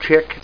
CHICK.mp3